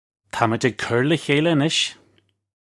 Pronunciation for how to say
Tah midge ig kur luh khayla a-nish (U)
This is an approximate phonetic pronunciation of the phrase.